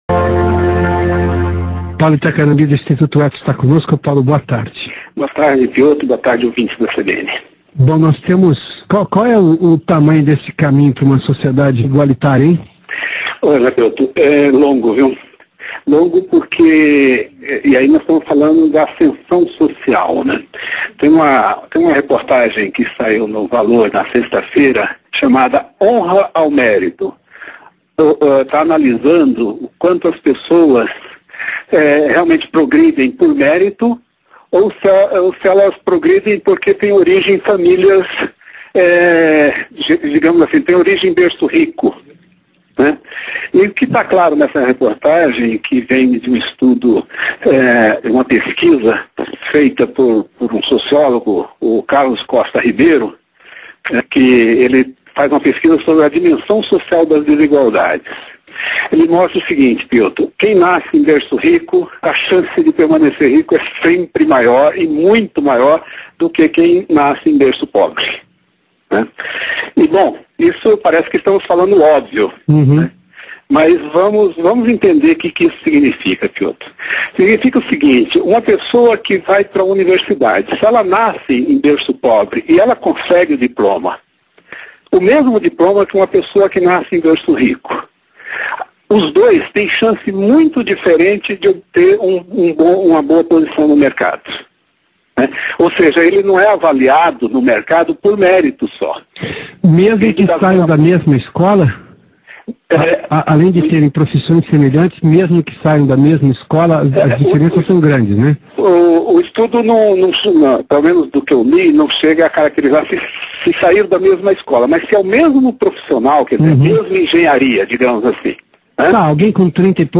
Março/2011 Responsabilidade Social - Bem nascidos ainda têm chances melhores de conseguir bom emprego Meio: Rádio CBN - RJ Mídia: Rádio Temas / Subtemas Desenv.